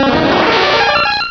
Cri de Paras dans Pokémon Rubis et Saphir.